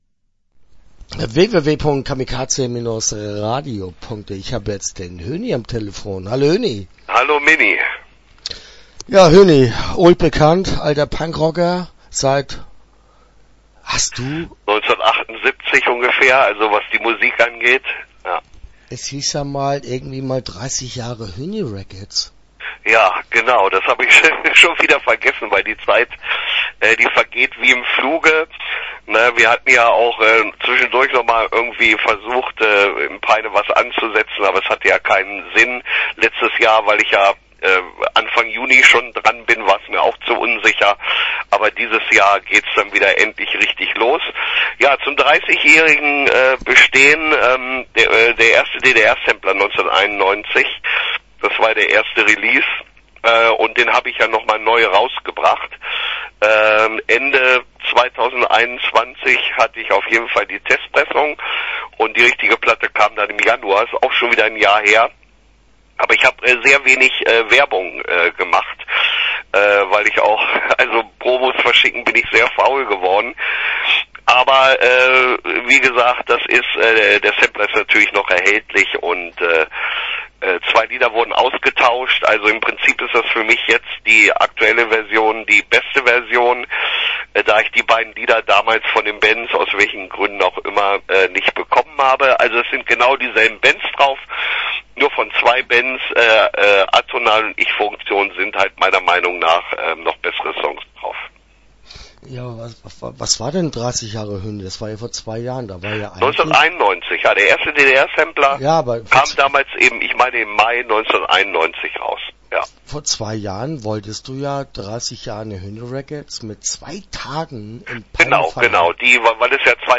Interview Teil 1